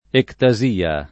vai all'elenco alfabetico delle voci ingrandisci il carattere 100% rimpicciolisci il carattere stampa invia tramite posta elettronica codividi su Facebook ectasia [ ekta @& a ] s. f. — anche ettasia , spec. nel sign. med.